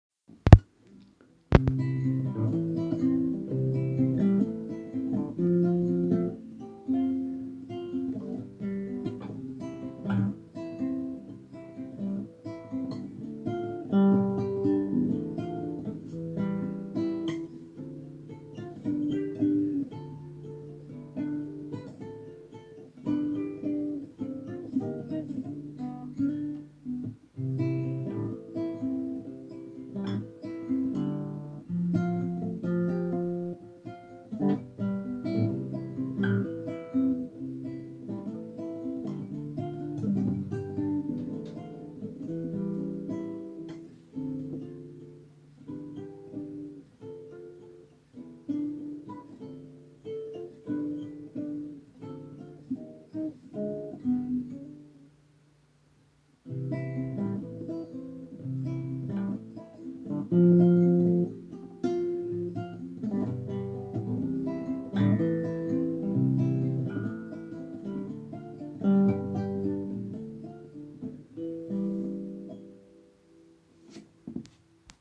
Anotace: Trošička humoru s tradičně tichým a nejistým kytarovým nástřelem:-)